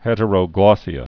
(hĕtə-rō-glôsē-ə, -glŏs-)